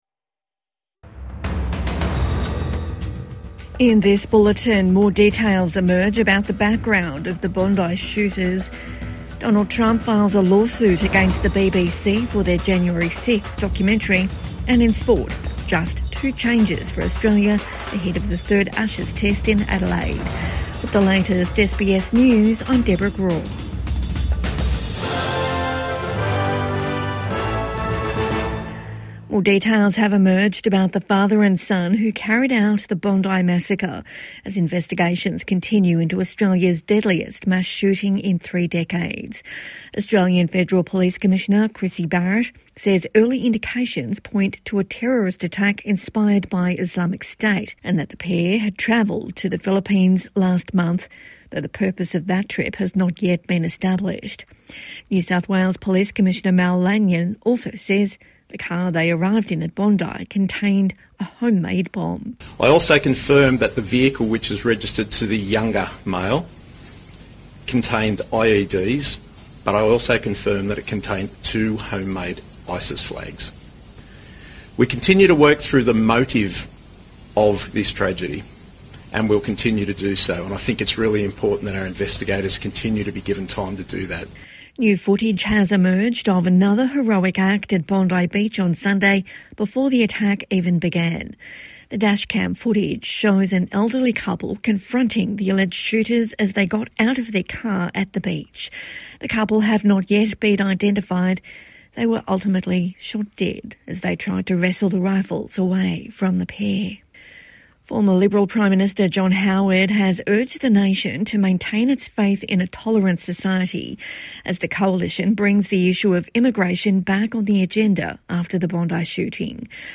More details emerge about extremist links of Bondi gunmen | Evening News Bulletin 16 December 2025